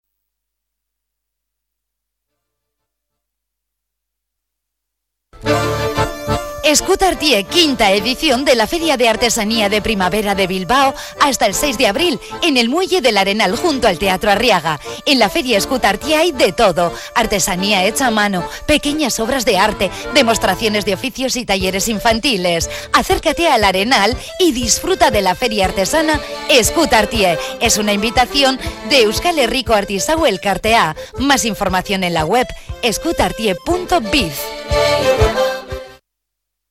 Cuña publicitaria en Radio Popular